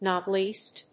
nòt léast